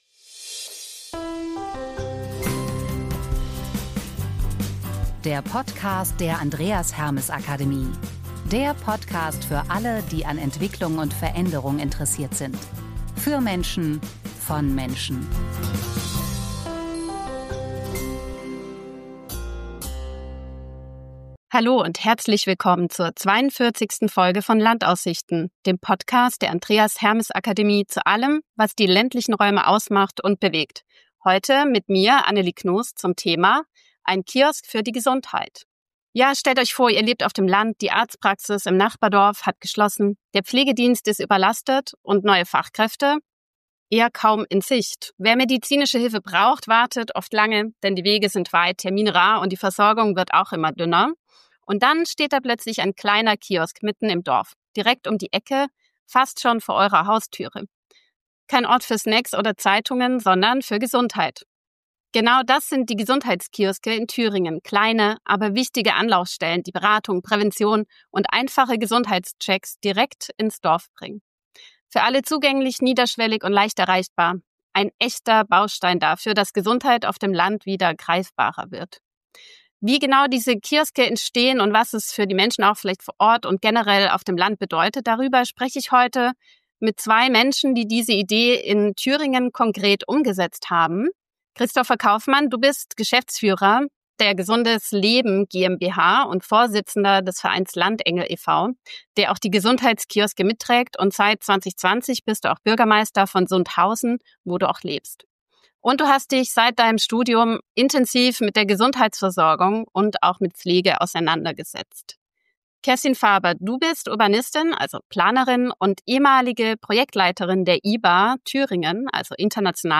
Im Gespräch geht es um die Vision einer ganzheitlichen Gesundheitsversorgung, die weit über Medizin hinausgeht.